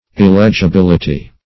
\Il*leg`i*bil"i*ty\